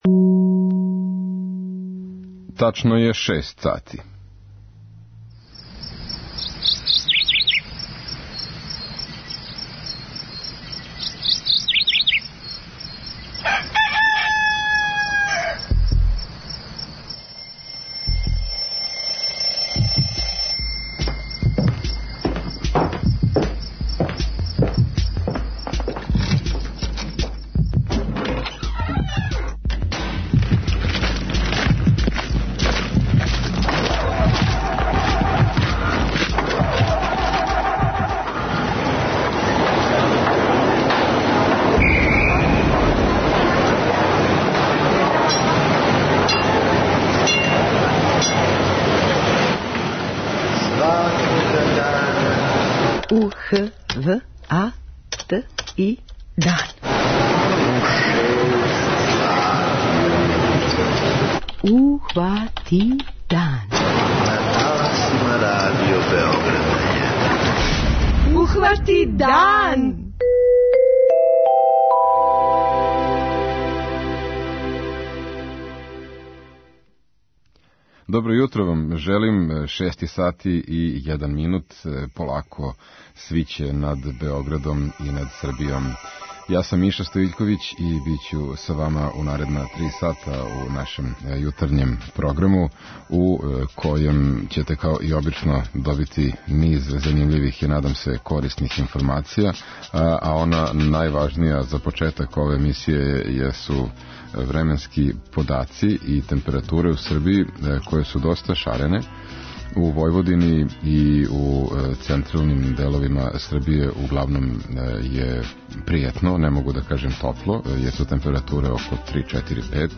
06:03 Јутарњи дневник, 06:35 Догодило се на данашњи дан, 07:00 Вести, 07:05 Добро јутро децо, 08:00 Вести, 08:10 Српски на српском, 08:45 Каменчићи у ципели
Јутарњи програм Радио Београда 1!